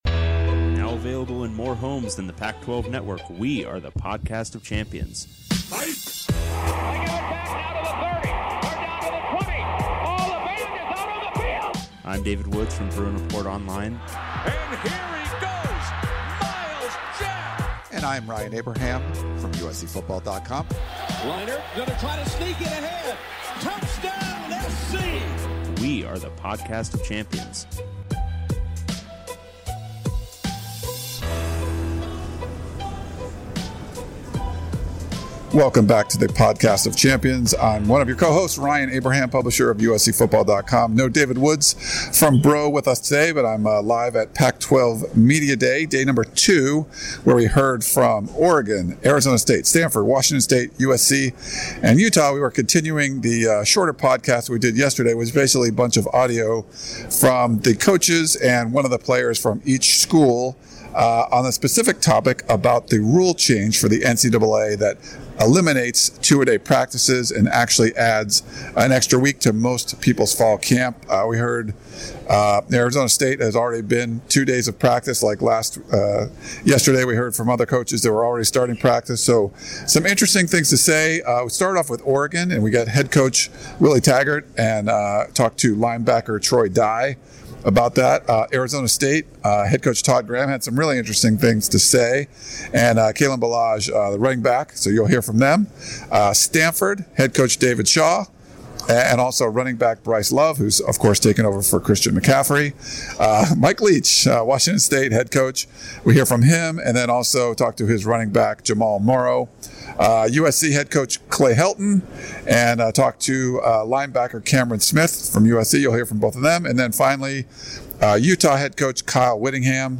From day two of Pac-12 Media Days coaches and players discuss the new NCAA rule that eliminates two-a-day practices in fall camp.